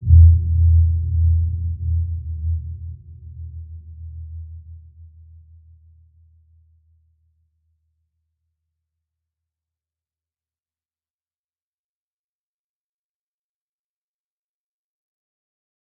Dark-Soft-Impact-E2-p.wav